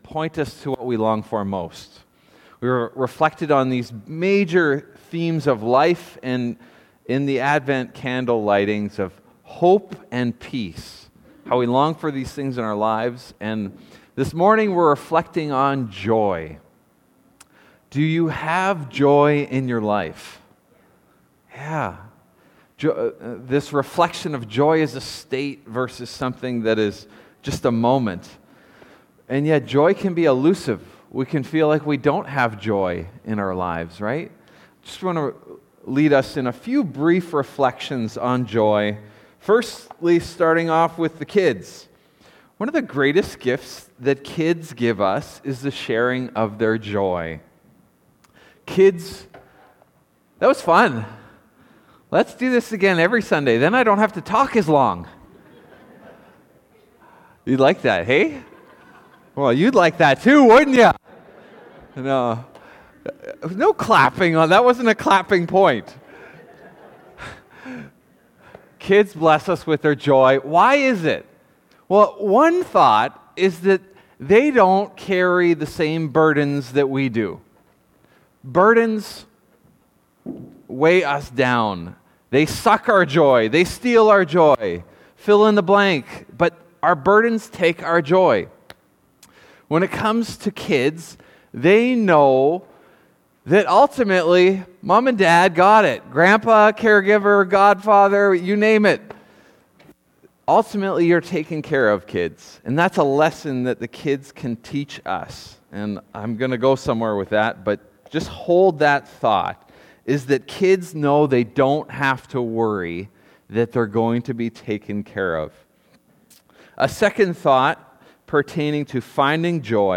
Sermons | Northstar Church